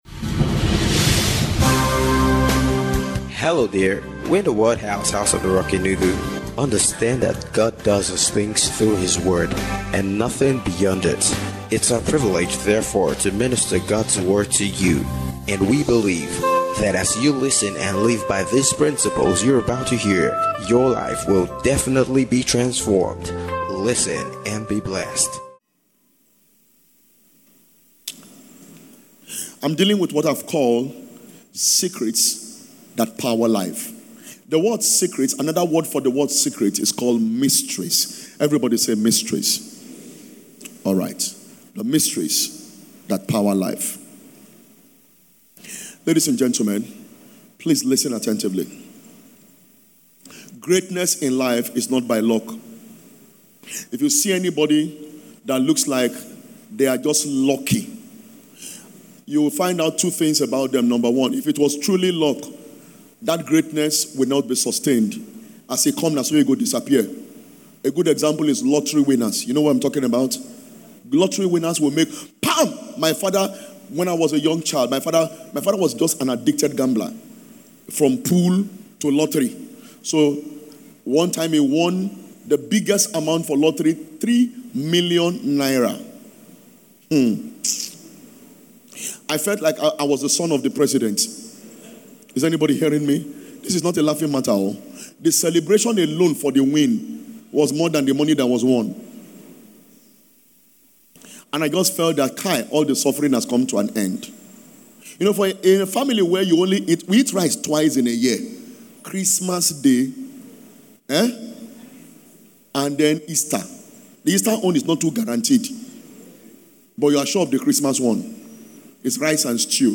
SECRETS THAT POWER LIFE (SUNRISE SERVICE)